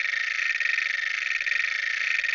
wee_car.mp3